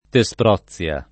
Tesprozia [ te S pr 0ZZL a ] top. f. (Gr.)